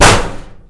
impact_2.wav